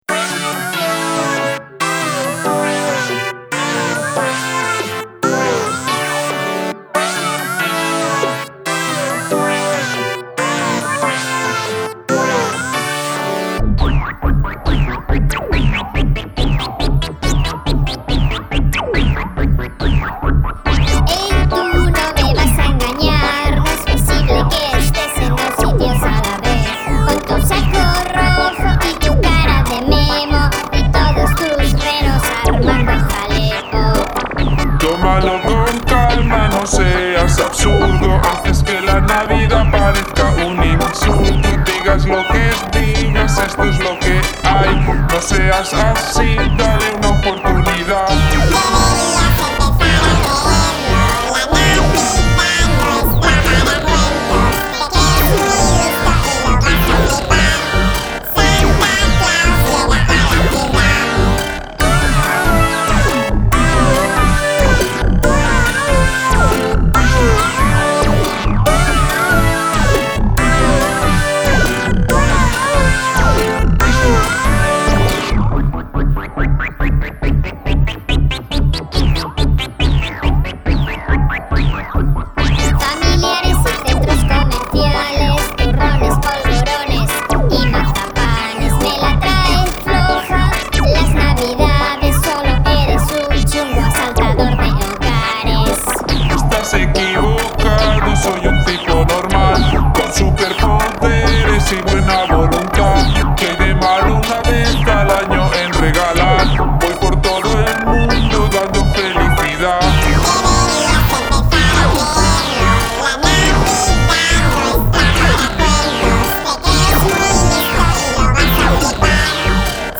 villancico
con voces